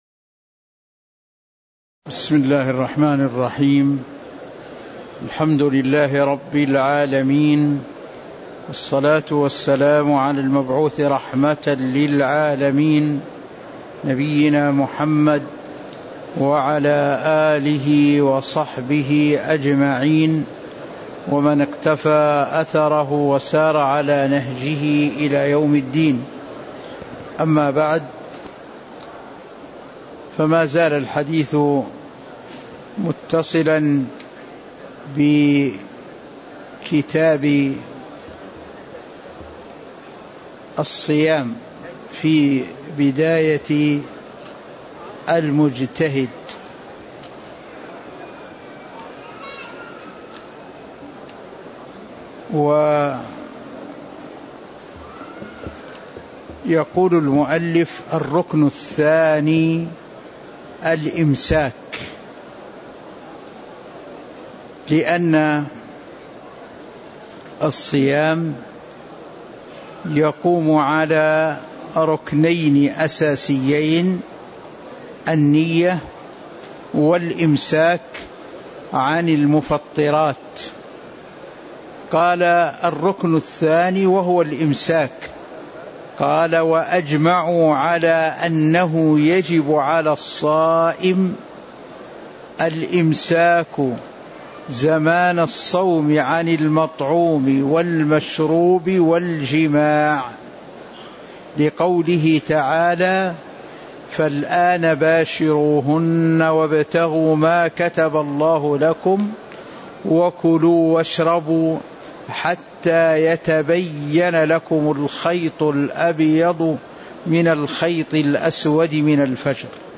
تاريخ النشر ٢٤ ربيع الثاني ١٤٤٦ هـ المكان: المسجد النبوي الشيخ